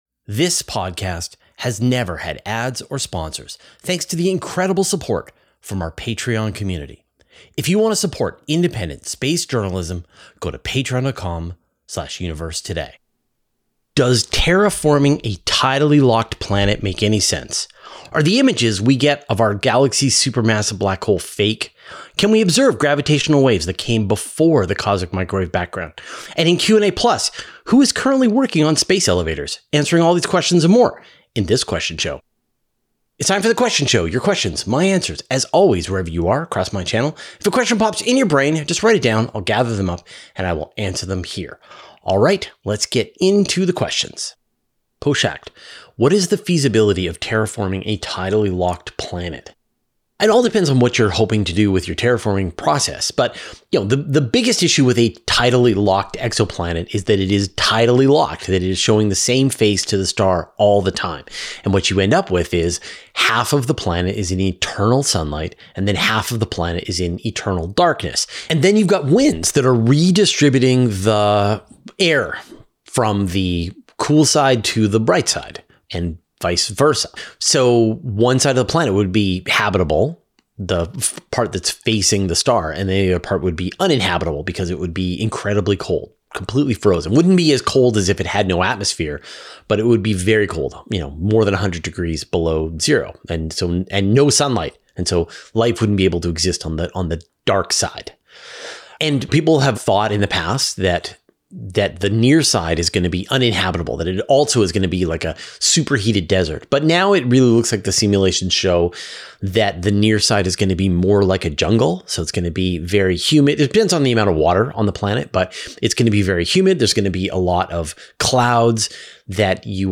Answering all these questions and more in this Q&A show.